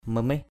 /mə-mih/ mamih mm{H [Cam M] 1.
mamih.mp3